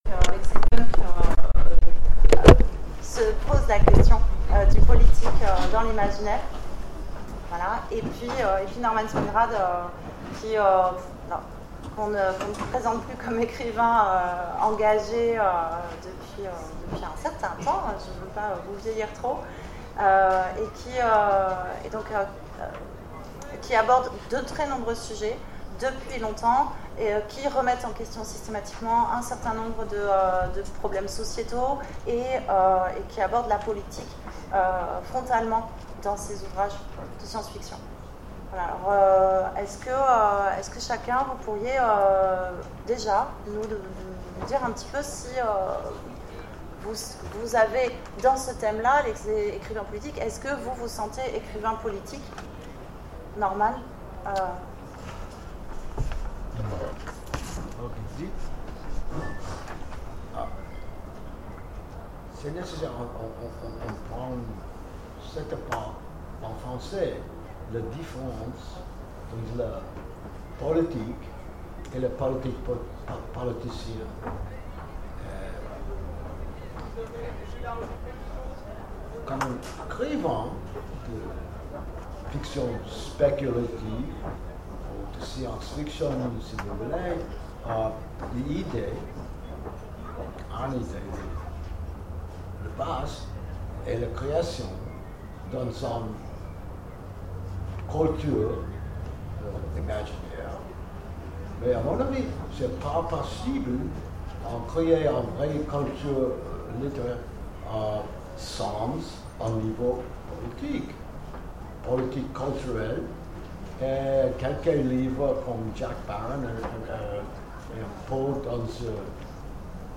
Imaginales 2016 : Conférence Auteurs d’imaginaires…
- le 31/10/2017 Partager Commenter Imaginales 2016 : Conférence Auteurs d’imaginaires…